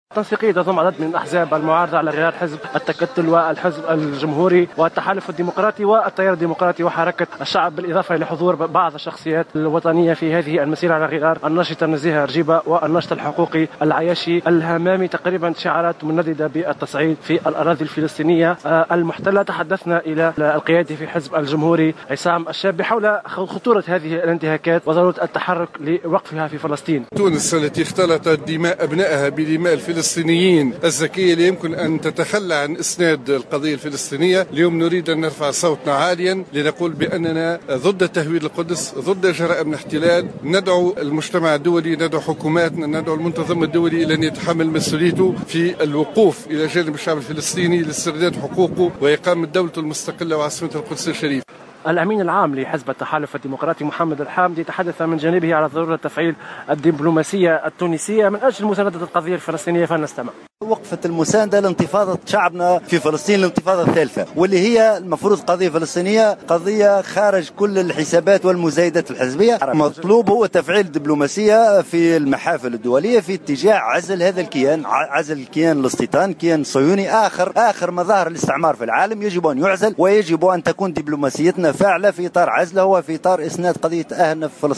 نظمت تنسيقية الأحزاب المعارضة التي تضم حزب التكتل والحزب الجمهوري والتحالف الديمقراطي وحركة الشعب وعدد من مكونات المجتمع المدني على غرار نزيهة رجيبة والعياشي الهمامي اليوم الجمعة 23 أكتوبر 2015 وقفة تضامنية مع الشعب الفلسطيني أمام المسرح البلدي بالعاصمة.
وقد رفع المشاركون في الوقفة التضامنية شعارات نددت بالتصعيد وبالانتهاكات التي ترتكبها سلطات الاحتلال في حق الشعب الفلسطيني. وندد القيادي في الحزب الجمهوري عصام الشابي في تصريح للجوهرة أف أم بجرائم الكيان الصهيوني مضيفا أن تونس ضد تهويد القدس وهي لن تتخلى عن مساندة القضية الفلسطينية.